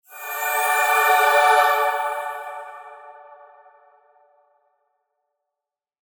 receipt_effect.mp3